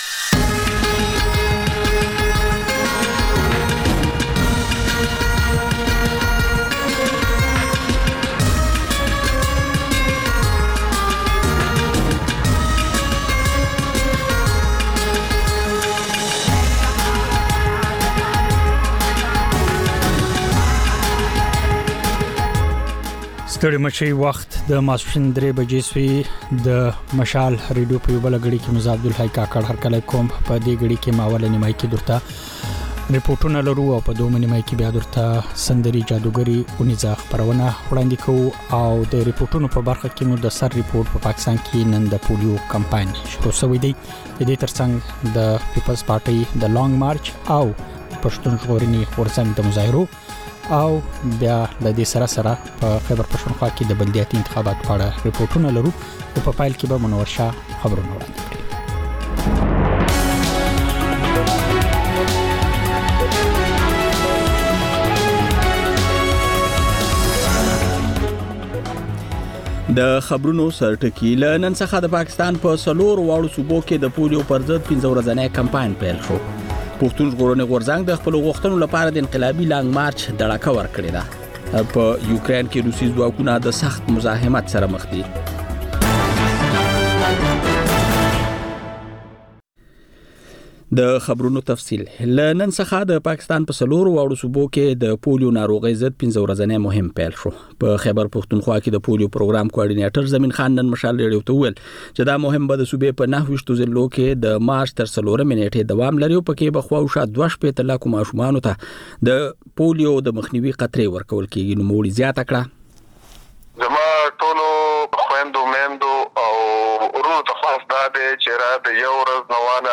د مشال راډیو درېیمه یو ساعته ماسپښینۍ خپرونه. تر خبرونو وروسته، رپورټونه، شننې، او رسنیو ته کتنې خپرېږي.